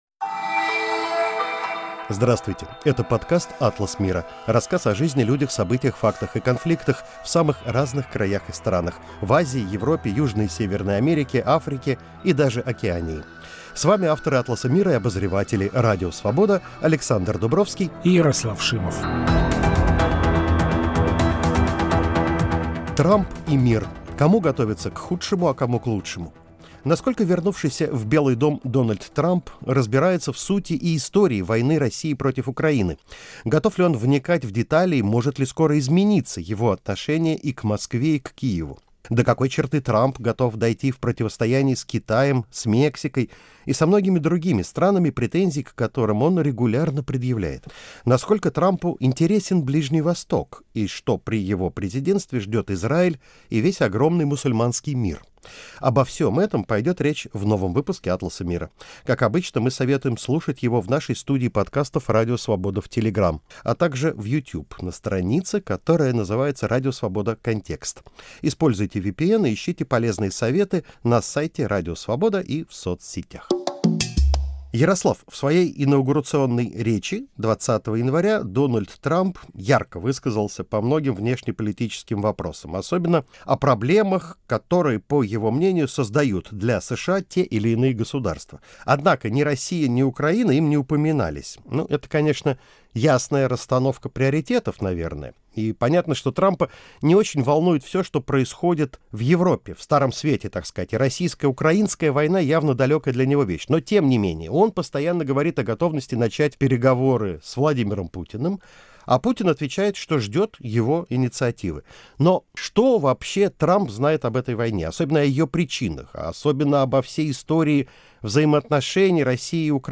Каждую неделю журналисты-международники беседуют о жизни, людях, событиях, фактах и конфликтах за пределами России и США.